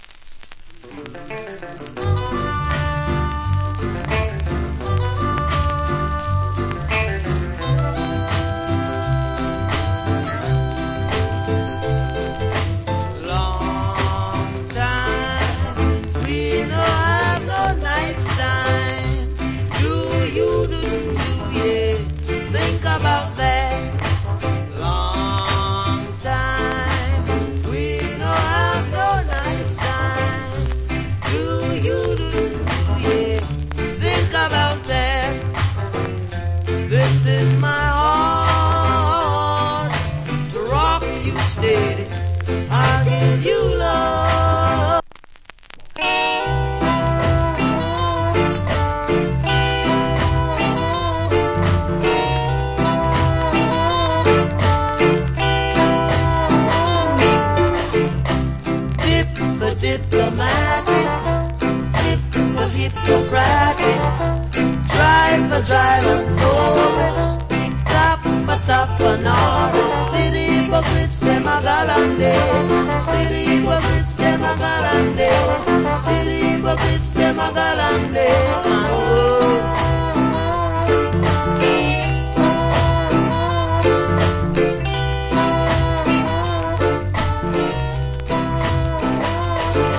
Great Rock Steady Vocal.